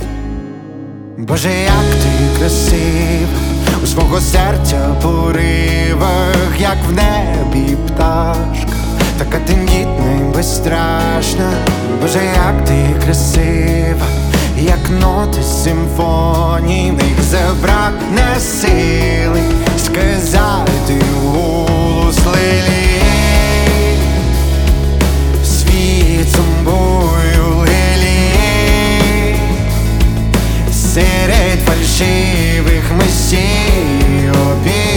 Жанр: Поп музыка / Украинские
Pop